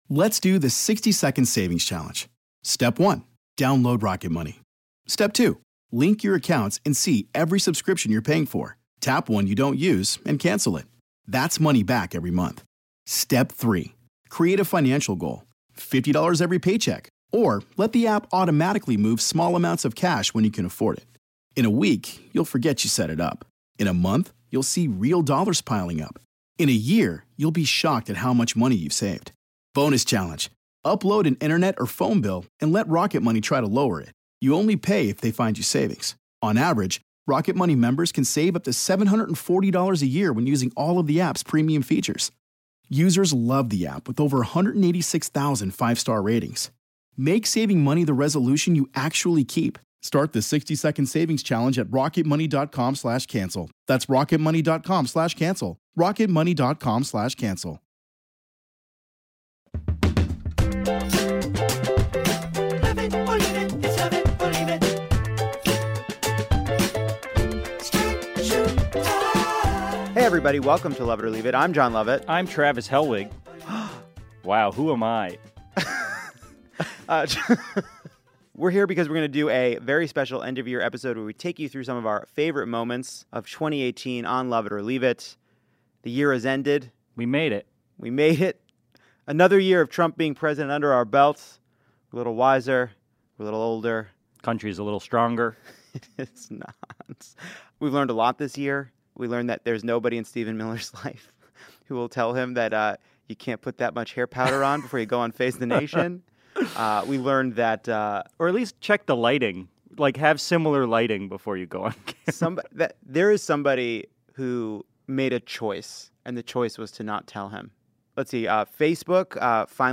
Plus a dramatic reading with Mandy Moore and Taylor Goldsmith as the Conways, Ezra Miller stops by to play a game and so much more.